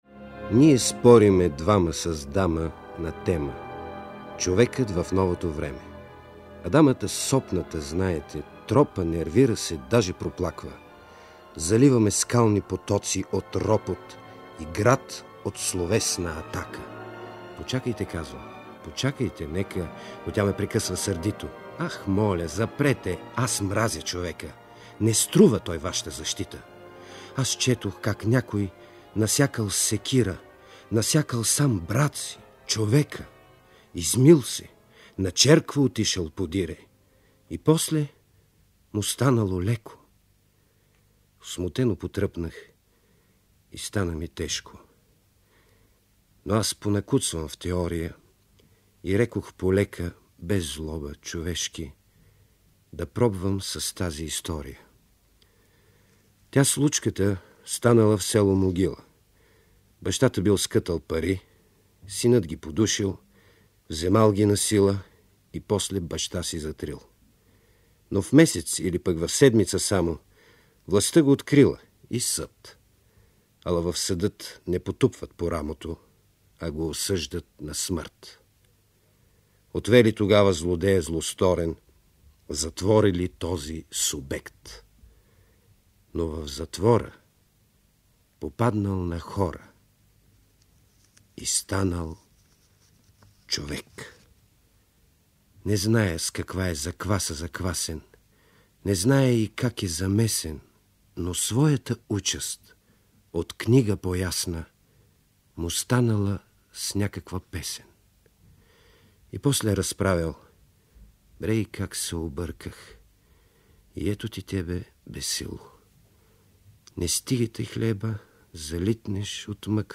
Вапцаровите поетически спорове, които той води с буржоазната интелигенция обаче, му дават повод да напише и стихотворението „Песен за човека“ (което изпълнява Георги Новаков):